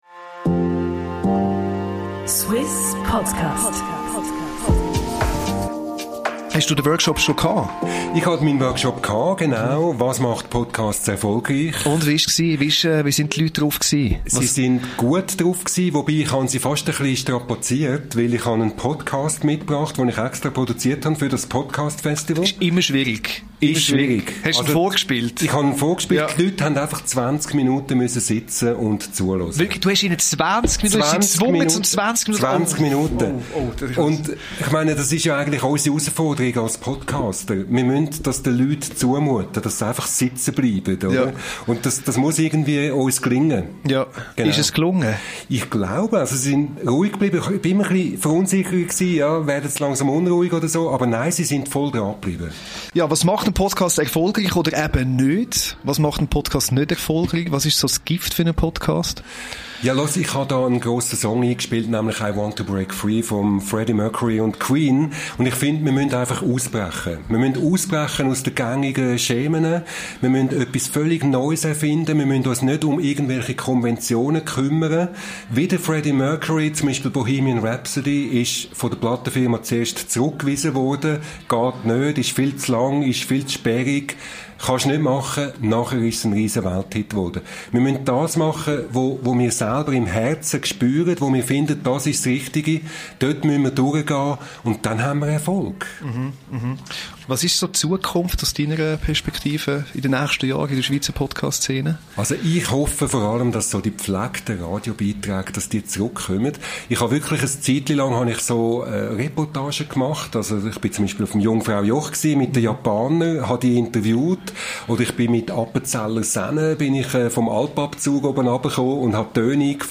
Thema hat er in seinem Workshop und im Interview am Suisse Podcast